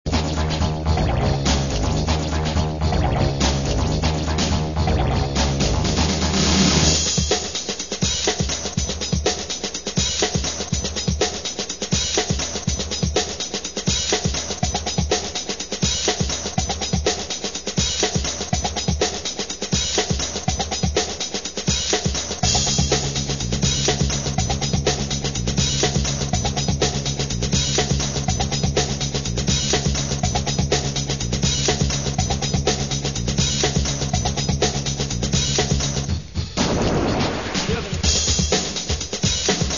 Segunda maqueta con tonos electro-rock bailables.
El Bajo eléctrico brilla